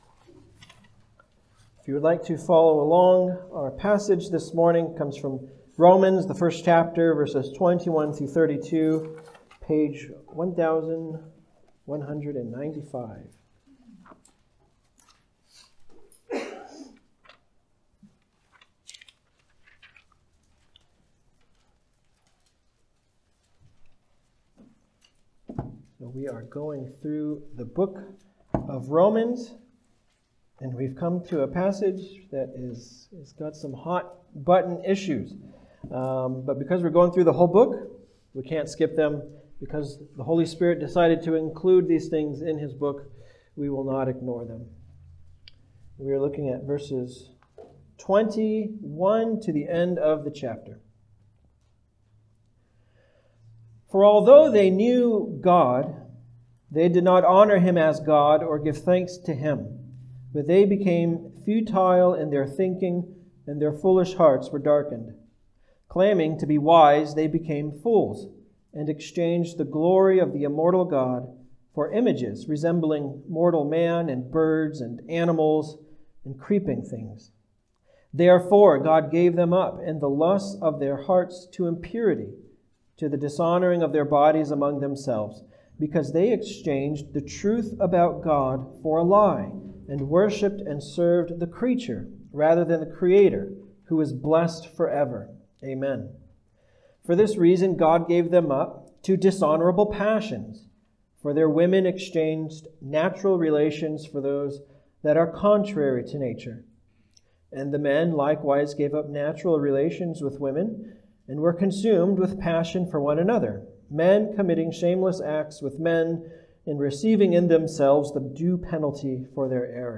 Passage: Romans 1:21-32 Service Type: Sunday Service